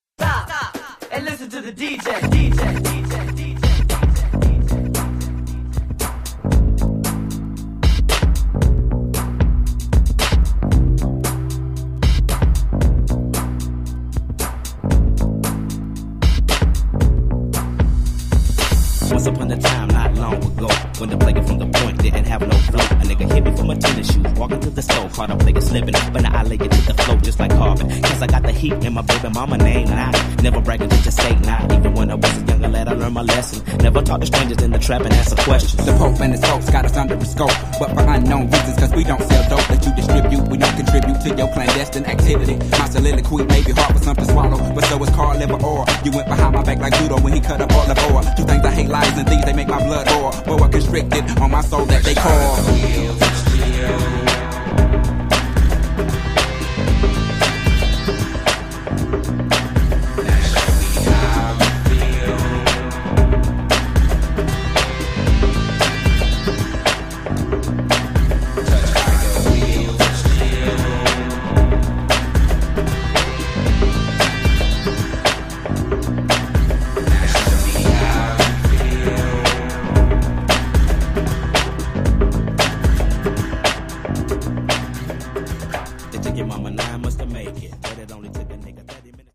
115 bpm